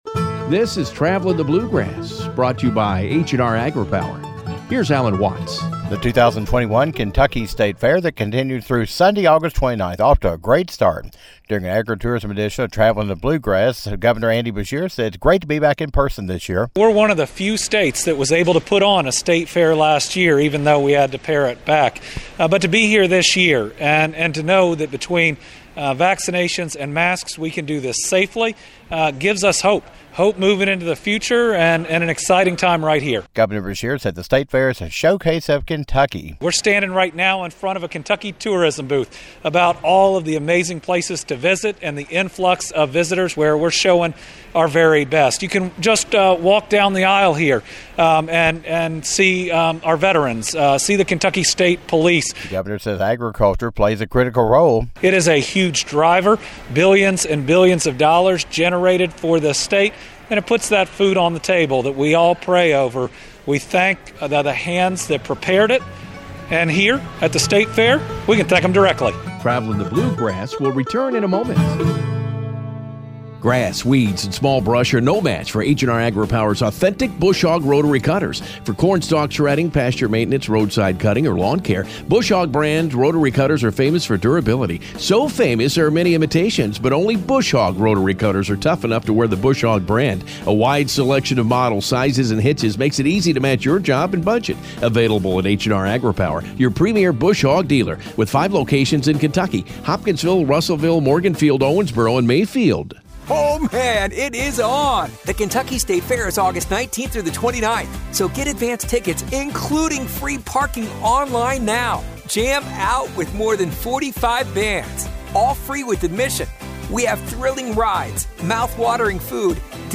Governor Andy Beshear says the 2021 Kentucky is the showcase for everything the state has to be proud of.  Governor Beshear talks during the latest Agritourism Edition about having a safe and healthy fair, what people can enjoy at the fair, and his favorite fair events.